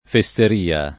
fesseria [ fe SS er & a ] s. f.